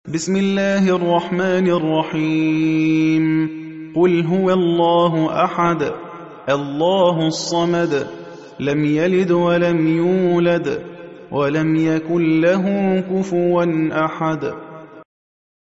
(روایت حفص)